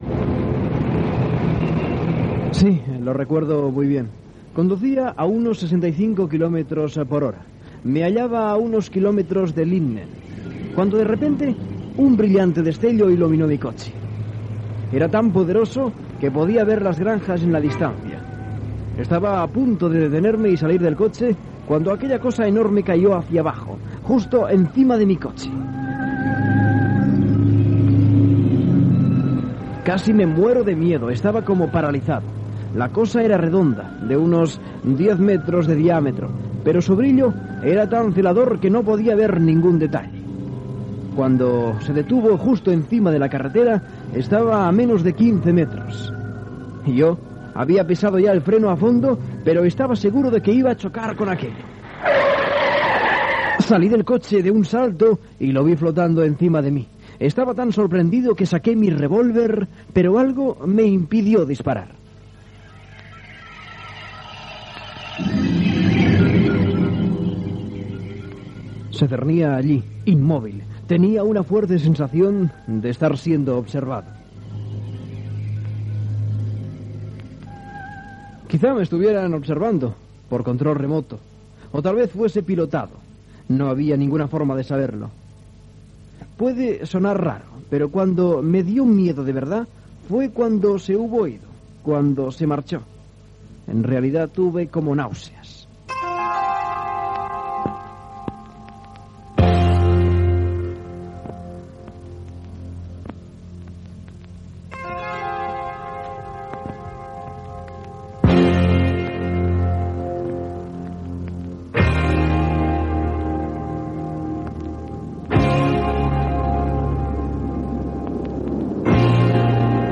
Recreació d'un relat d'un avistament OVNI als EE.UU. Presentació de l'espai dedicat al fenòmen OVNI i descripció de més casos d'avistaments.